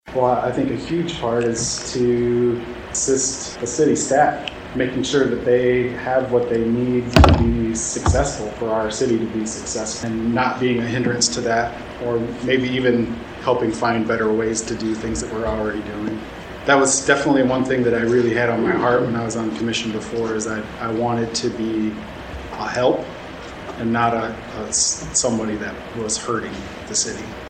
During a Wednesday night work session, the Commission interviewed Justin Wickey for a vacant seat to represent the Second Precinct.